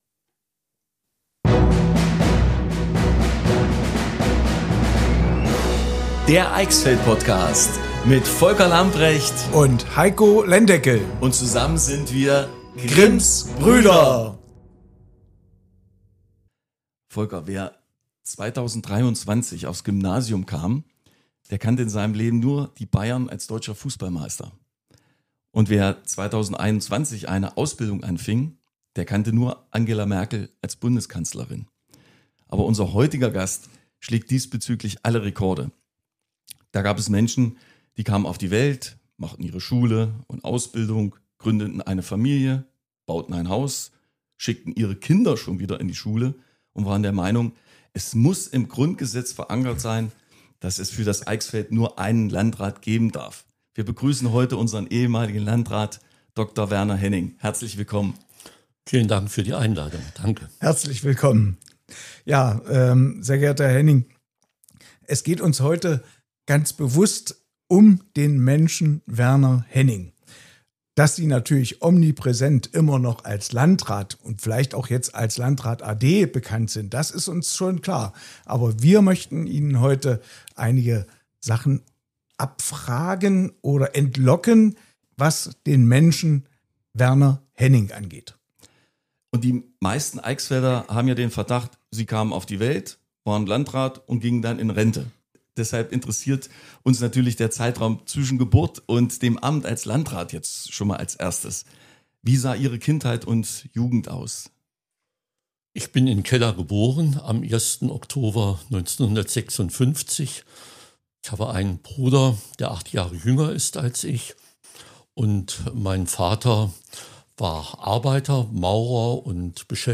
Beschreibung vor 6 Monaten Gut ein Jahr nach dem er den Landratsposten an seine Nachfolgerin übergeben hat, erzählt uns Dr. Werner Henning über seine mehr als 34 jähre Amtszeit als Landrat unseres Eichsfelds. Wir erfahren aus seiner Kindheit, Jugend und seiner Zeit vor seinem Amt als Landrat. Auch erzählt uns Herr Henning über Familie, Hobbys und seinem neuen Leben als Rentner.